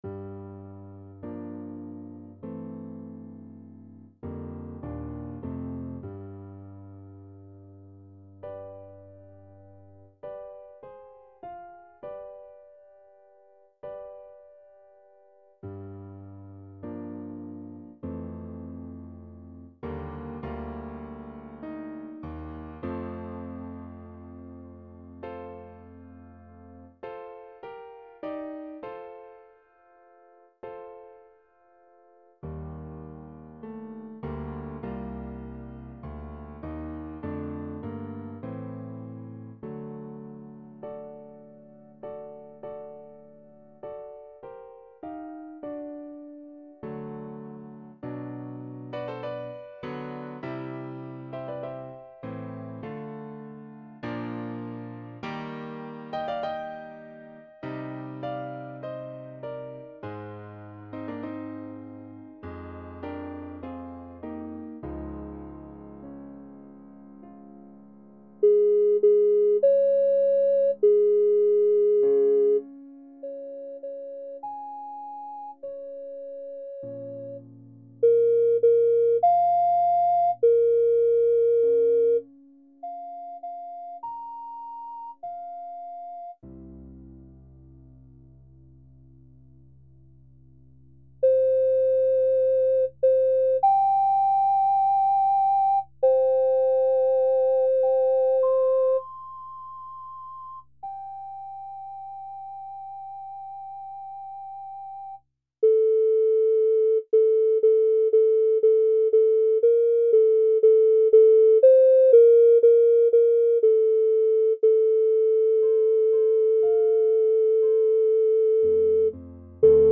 ソプラノ用（他パートなし）